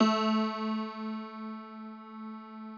53r-pno09-A1.wav